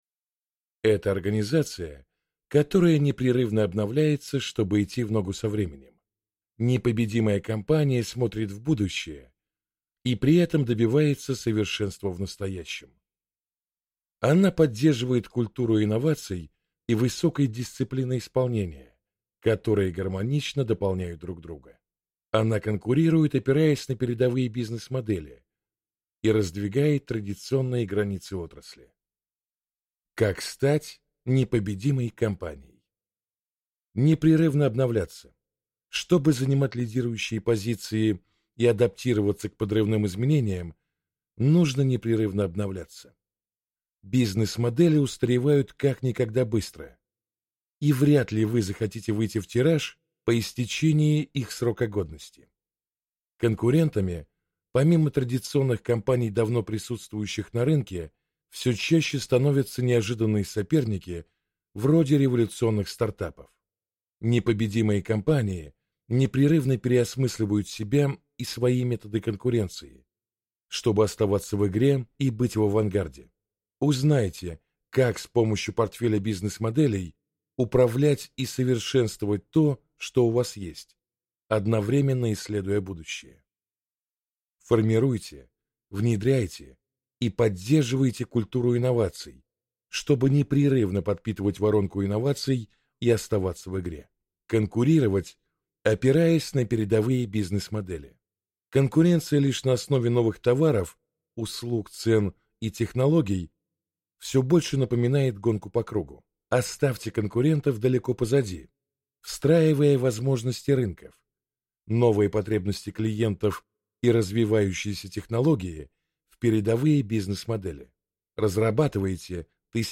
Аудиокнига Непобедимая компания. Как непрерывно обновлять бизнес-модель вашей организации, вдохновляясь опытом лучших | Библиотека аудиокниг